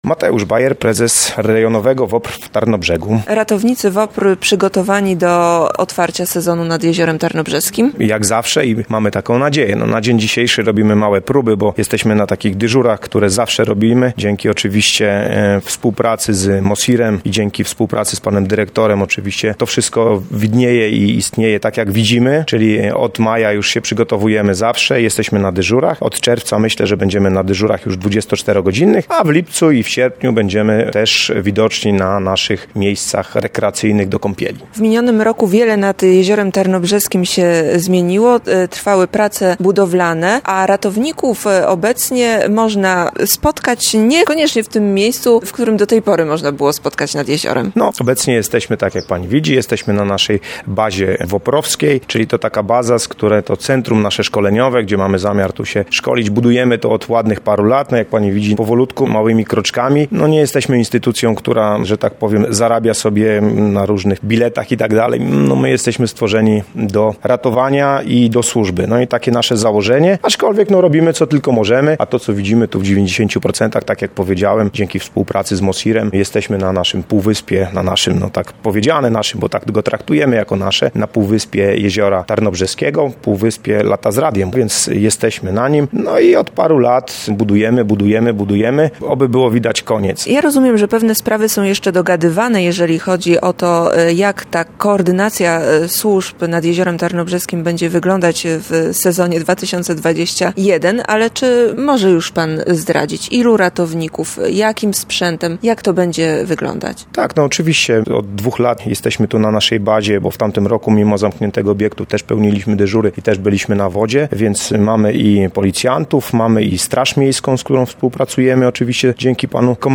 Rozmowa.